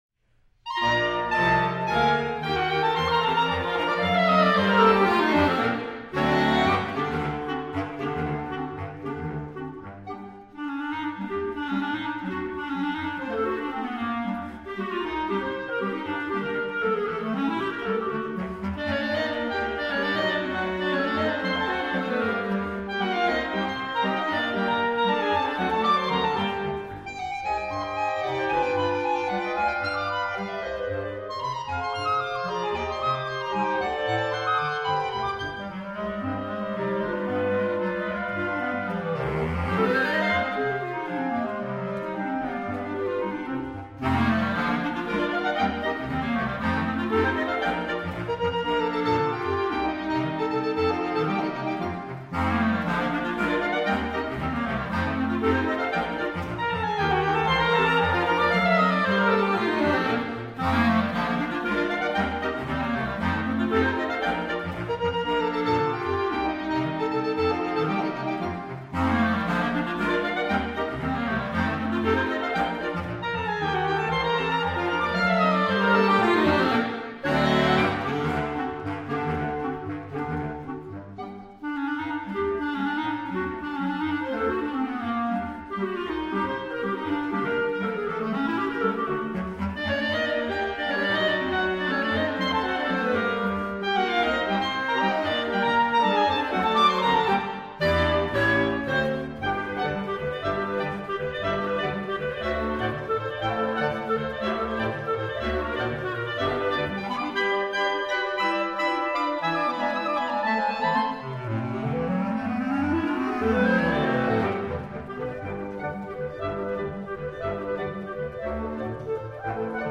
Latin style song